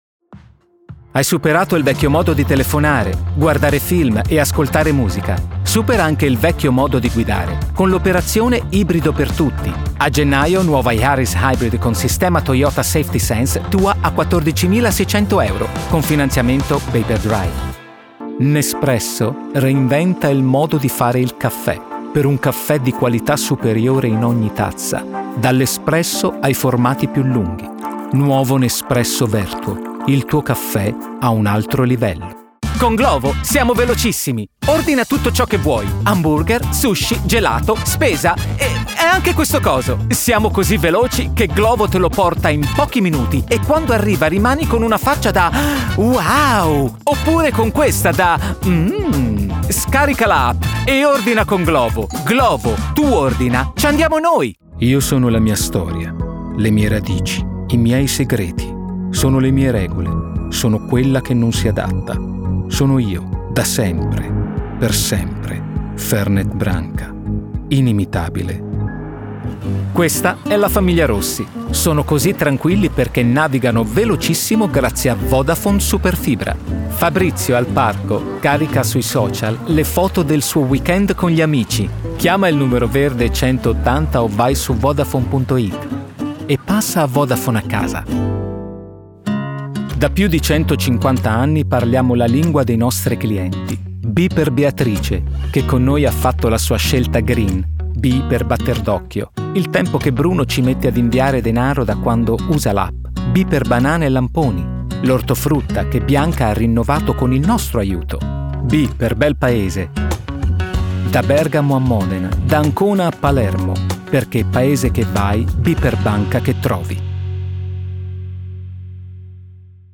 VOICE REEL
Italian actor highly proficient in English.